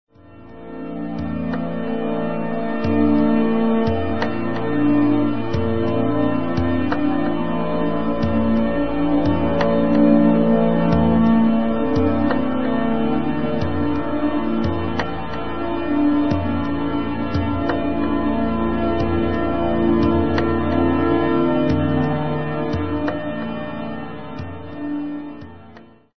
Compelling compositions, haunting and
mysterious